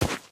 Divergent / mods / Footsies / gamedata / sounds / material / human / step / t_earth4.ogg